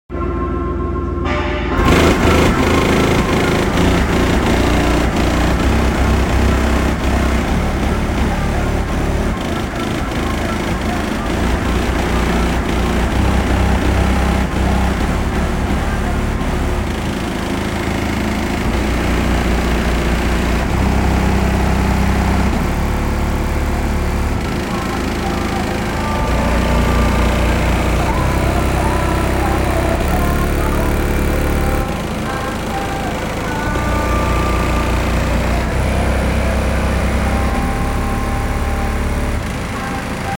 27 hz passenger side sealed sound effects free download